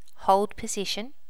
Initial check in of the sounds for the notify plugin.